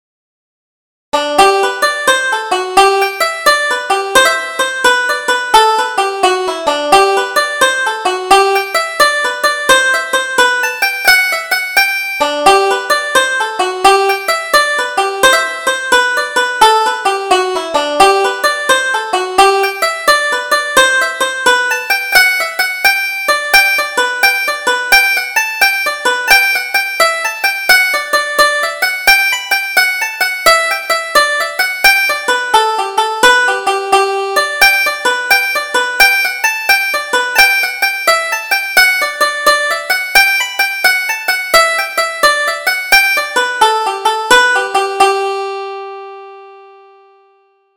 Double Jig: The Tongs by the Fire